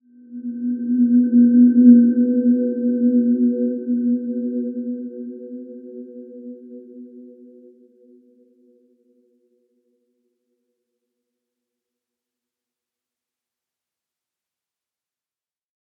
Dreamy-Fifths-B3-p.wav